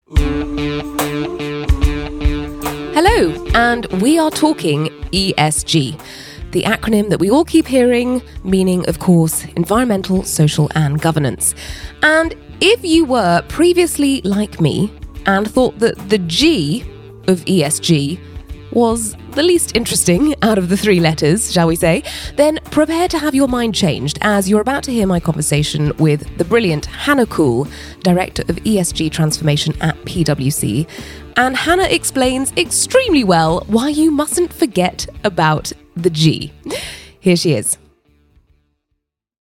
Voice Samples: Podcast Host Sample 01
female